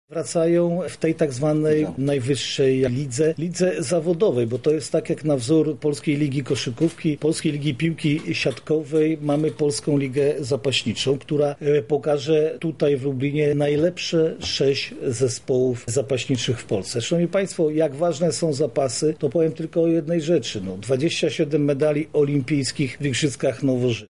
O tym, jak ważne są zapasy, mówi marszałek województwa lubelskiego Jarosław Stawiarski: